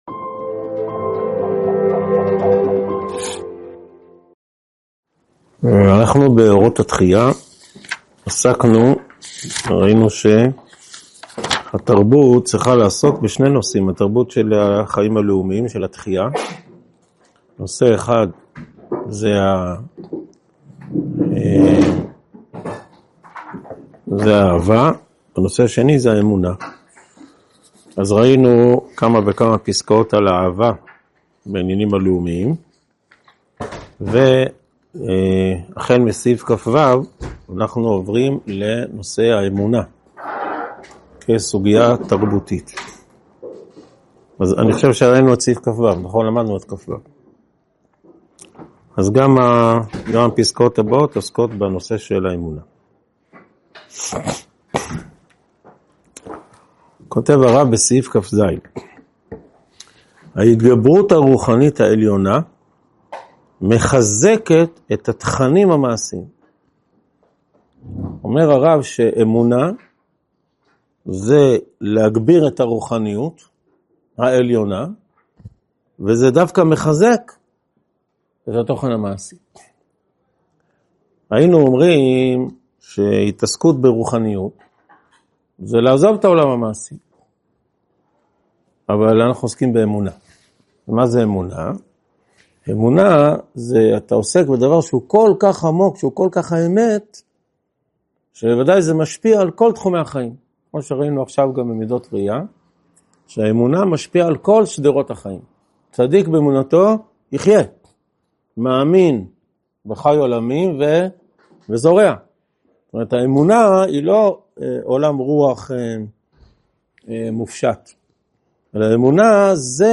הועבר בישיבת אלון מורה בשנת תשפ"ד.